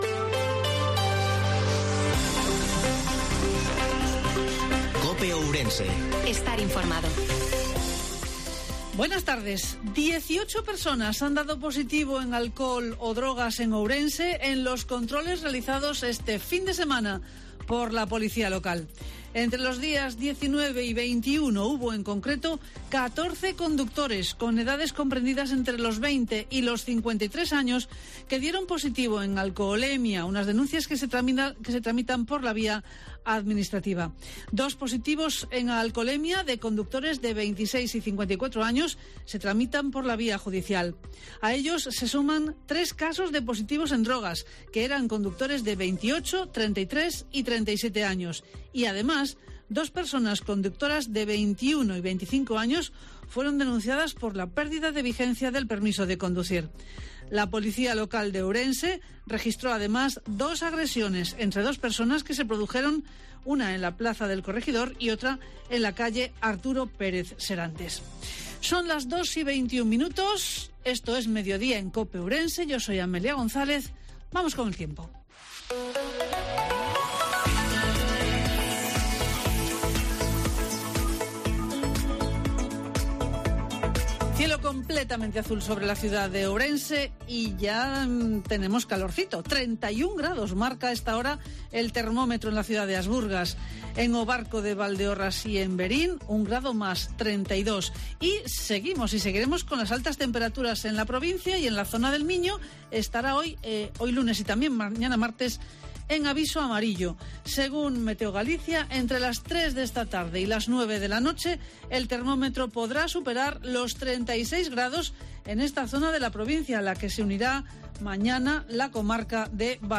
INFORMATIVO MEDIODIA COPE OURENSE-22/08/2023